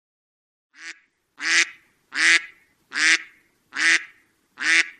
Woodpecker Tapping
Woodpecker Tapping is a free animals sound effect available for download in MP3 format.
581_woodpecker_tapping.mp3